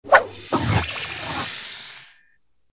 Converted sound effects